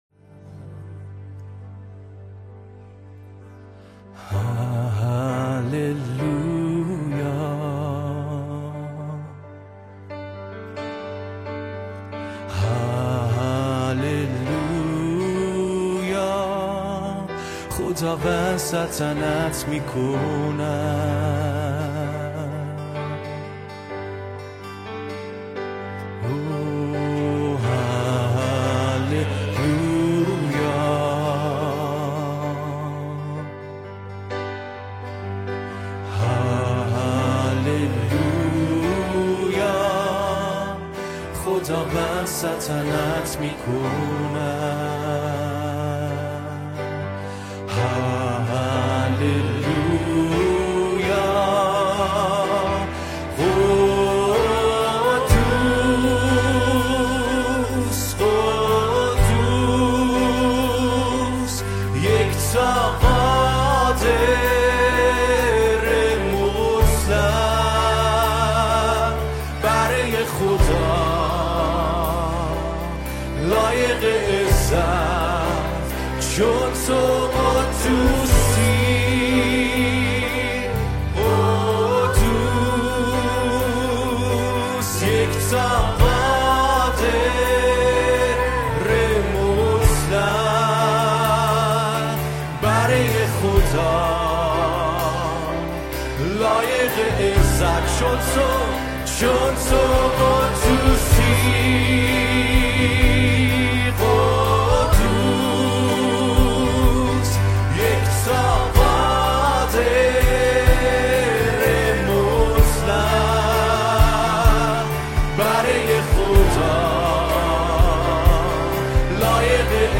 ماژور
Major